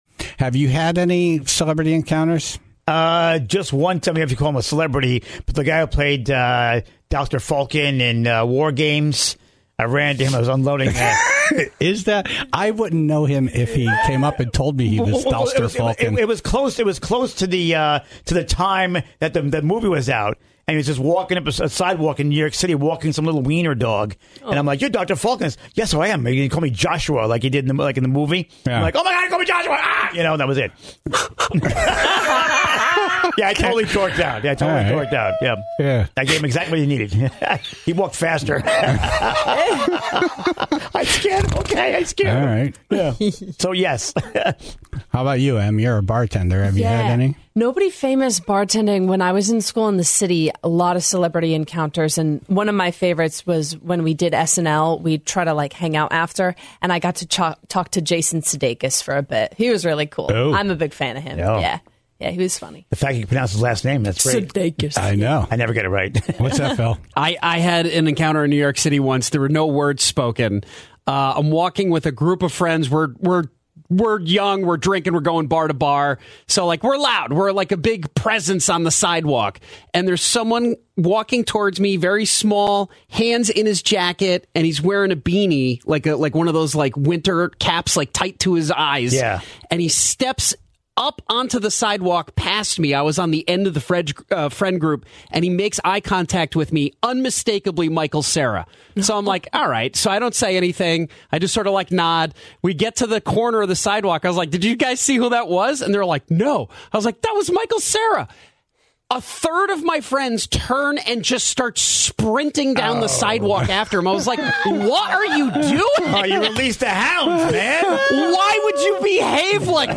asked the Tribe to call in with their celebrity encounter stories. Spotting a rock star at Disney, parking the car for an MLB all-star, and having a tortellini salad stolen by an Oscar winner.